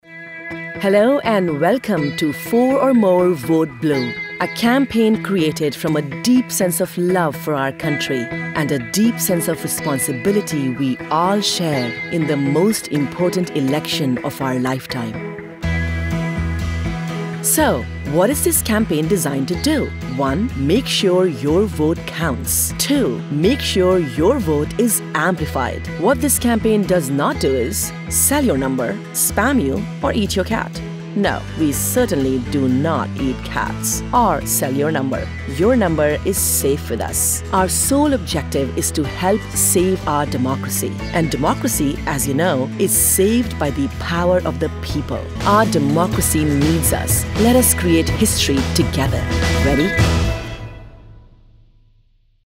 She can modulate her voice to be serious, cheeky, matter-of-fact, motivational, exhorting or whatever else the content demands.
A LITTLE SECRET - Did you know that using a TransAtlantic voice (not too American, not too British, but a bit of both) perks up the ears of the listener helping your message stand out from the crowd?